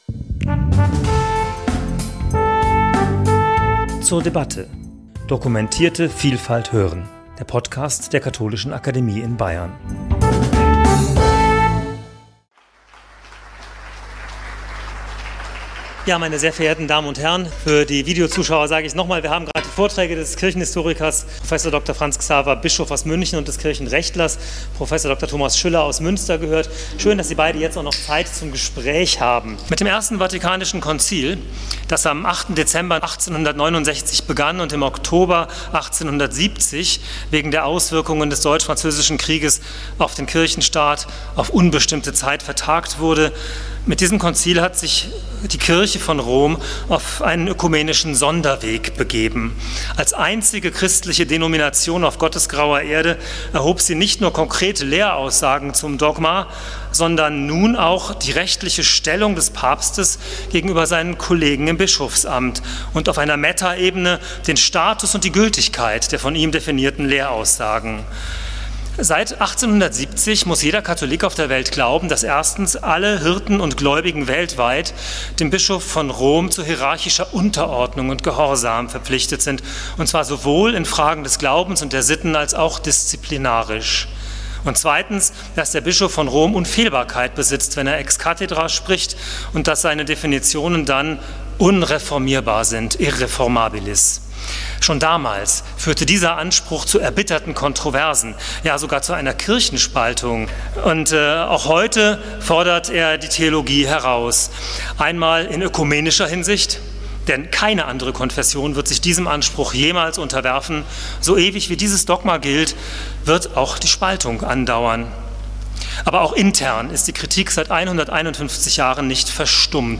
Gespräch zum Thema '150 Jahre Unfehlbarkeit' ~ zur debatte Podcast
Das Gespräch zum Thema '150 Jahre Unfehlbarkeit' fand am 20.5.2021 in der Katholischen Akademie in Bayern statt.